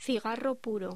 Locución: Cigarro puro